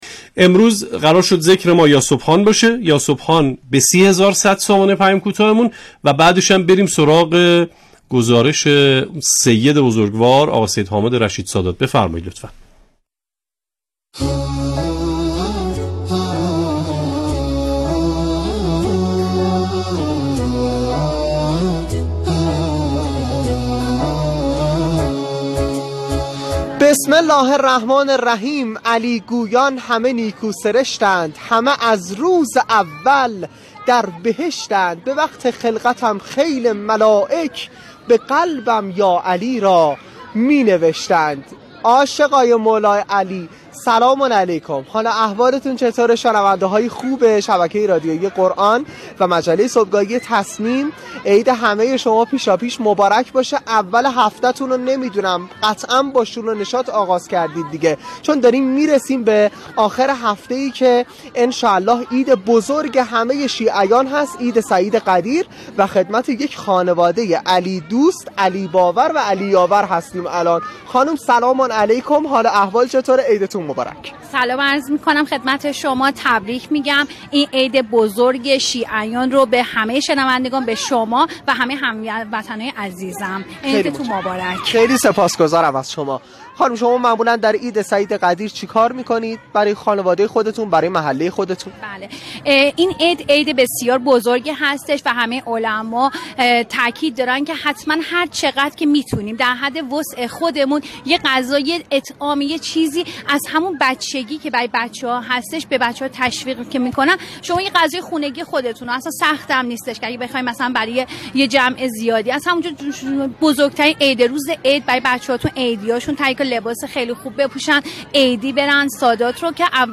مجله صبحگاهی زنده «تسنیم» رادیو قرآن در ایام دهه ولایت و امامت با آیتم‌های جذاب و پخش اناشید و همخوانی‌ها در وصف مولای متقیان حضرت علی (ع) و گزارش از حال و هوای غدیریه محلات به روی آنتن می‌رود.